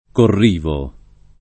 corrivo [ korr & vo ]